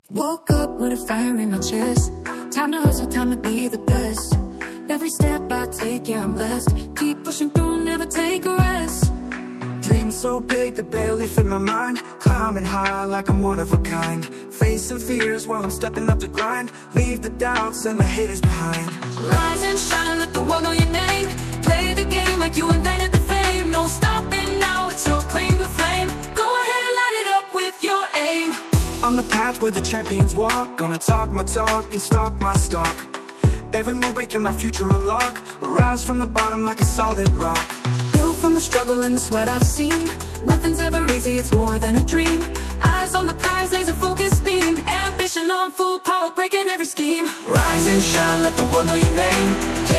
Podróż przez miejskie rytmy i głębokie emocje.